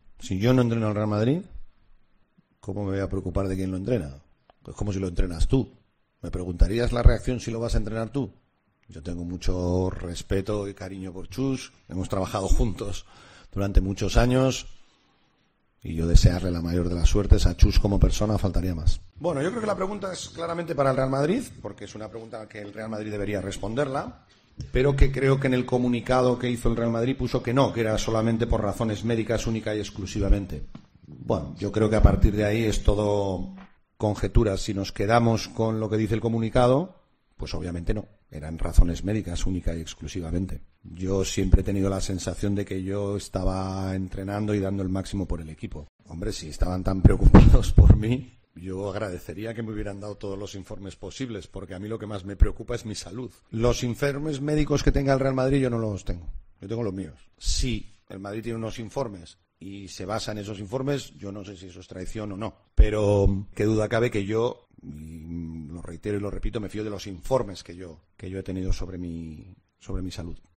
Presentación de su Campus